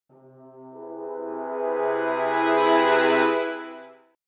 Звуки подозрительные, музыка
Погрузитесь в атмосферу тайны и саспенса с нашей коллекцией подозрительной музыки и звуков.
Suspense 5